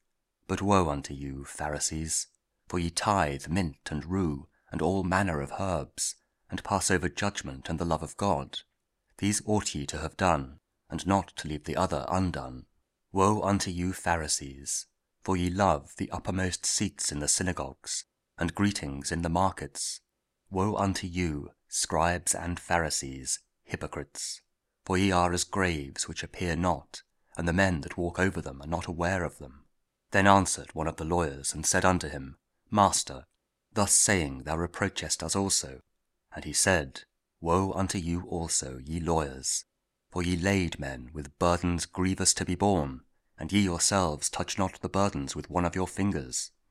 Luke 11: 42-46 – Week 28 Ordinary Time, Wednesday (King James Audio Bible KJV, Spoken Word)